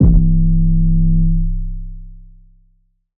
SOUTHSIDE_808_malfunction_C.wav